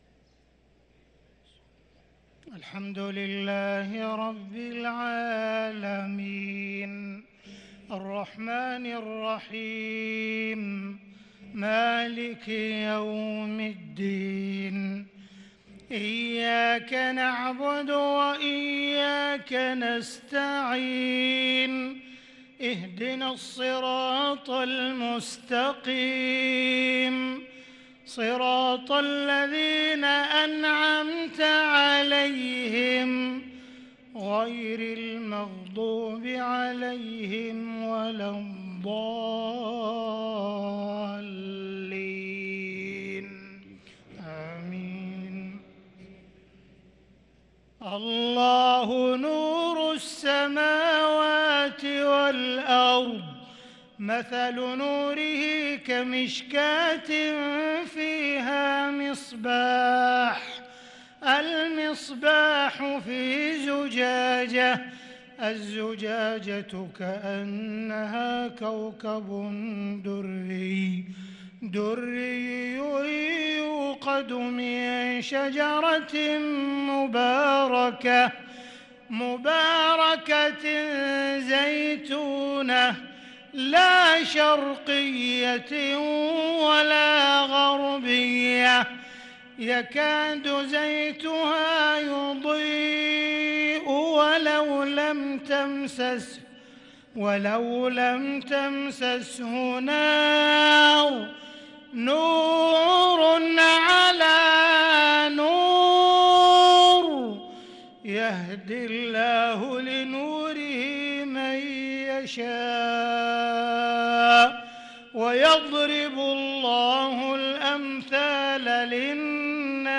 صلاة المغرب للقارئ عبدالرحمن السديس 22 رمضان 1443 هـ
تِلَاوَات الْحَرَمَيْن .